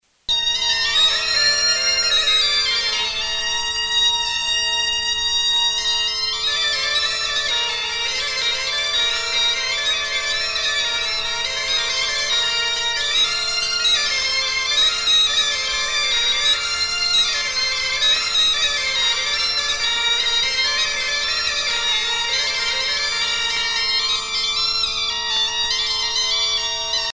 danse : chibreli
Pièce musicale éditée